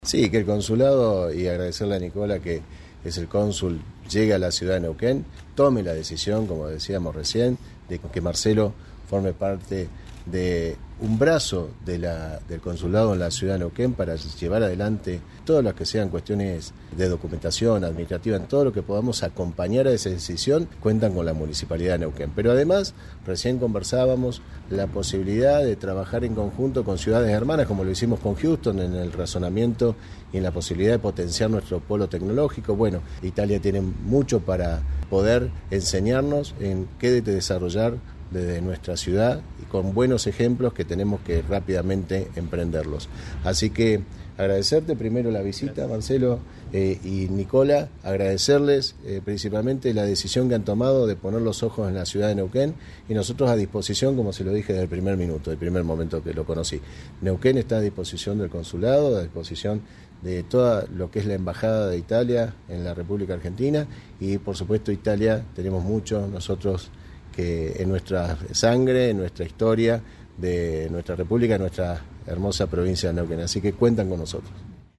Mariano Gaido, Intendente.
Mariano-Gaido-EDITADO-Visita-Consul-Italia.mp3